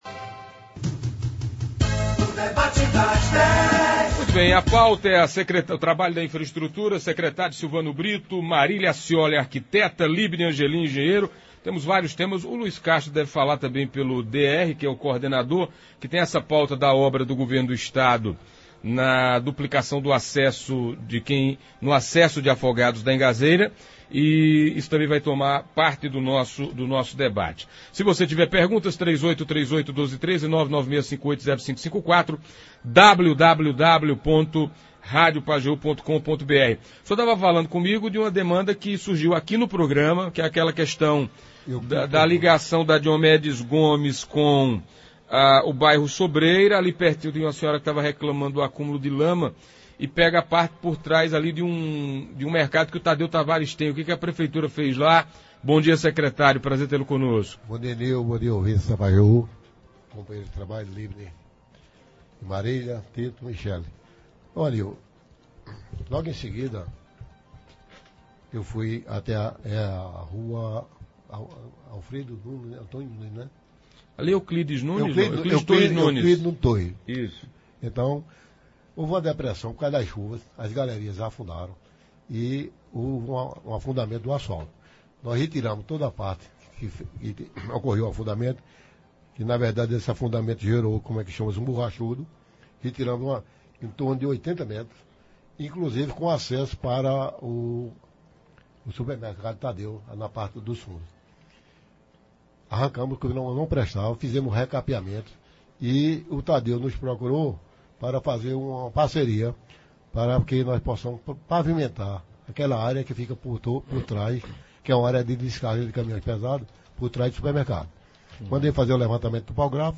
Também responderam a perguntas e tiraram dúvidas dos ouvintes e internautas da Pajeú.